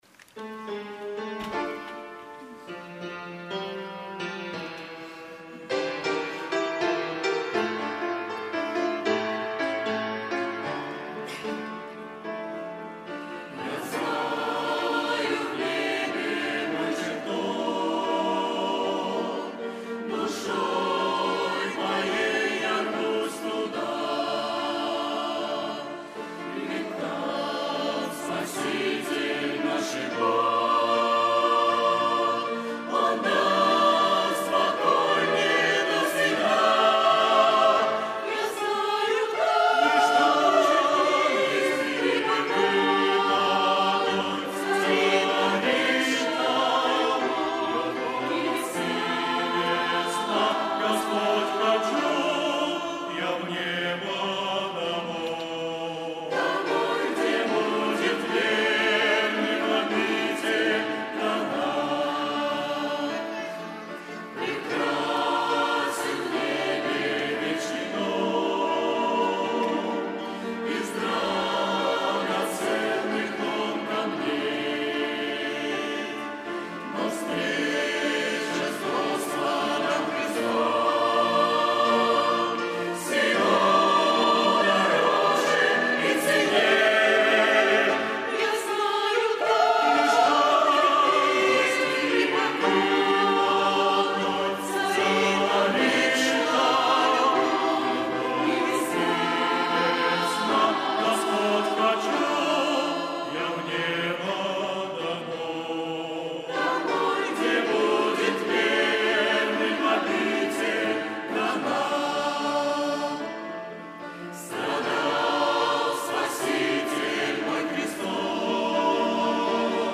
Богослужение 29.01.2023
Я знаю, в небе мой чертог - Хор (Пение)[